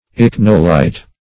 Search Result for " ichnolite" : The Collaborative International Dictionary of English v.0.48: Ichnolite \Ich"no*lite\, n. [Gr.
ichnolite.mp3